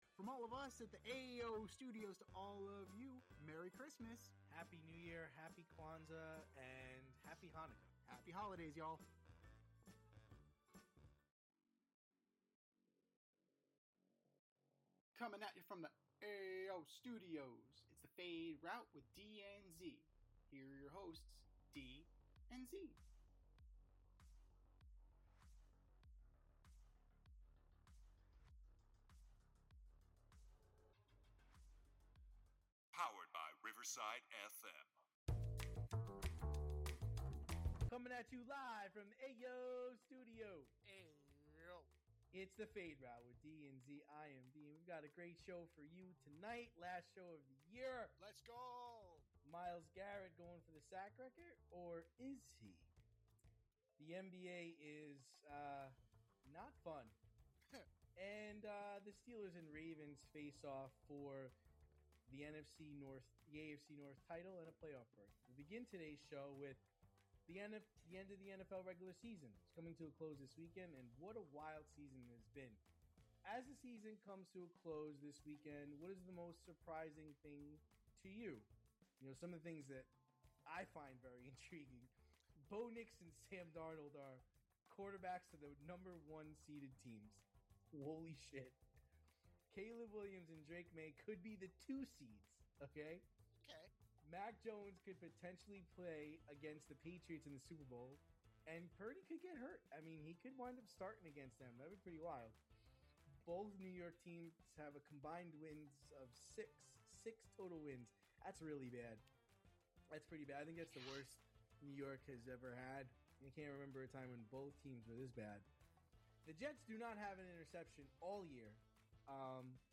two veteran sports aficionados and lifelong friends, as they dissect the week’s top stories with wit and a touch of New York flair.